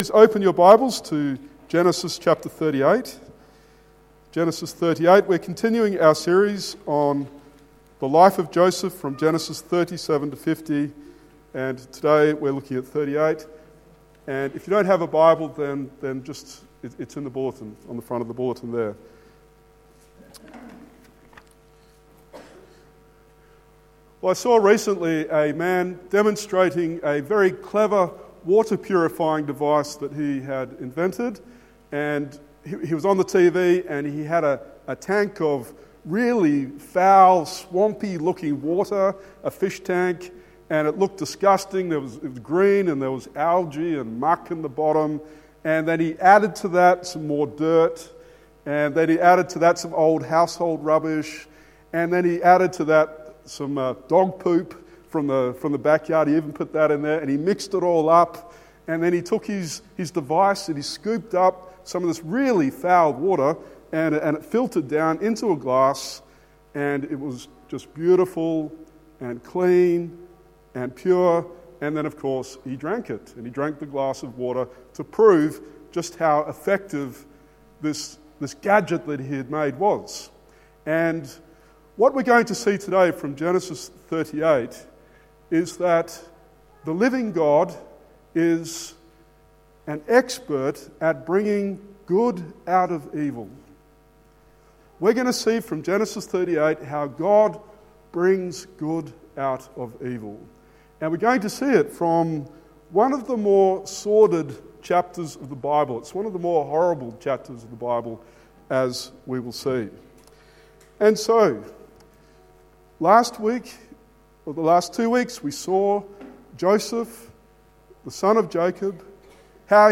Genesis 38:1-30 Sermon